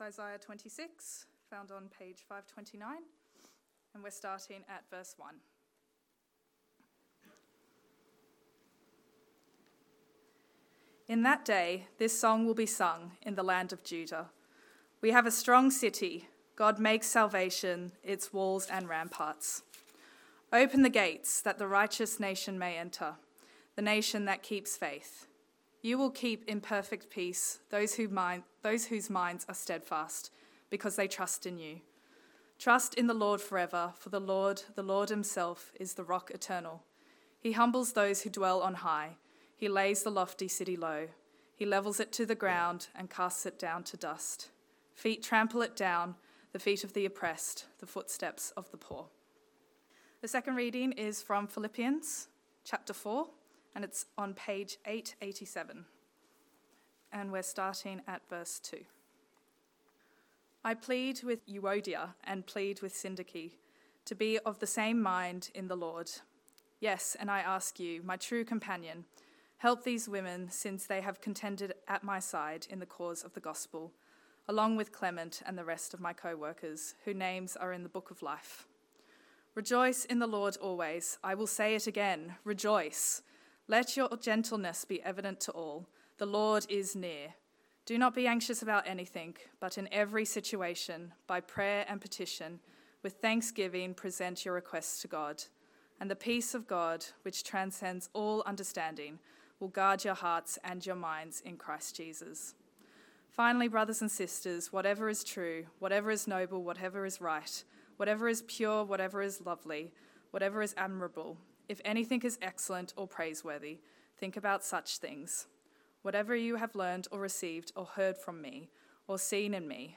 Sermon Video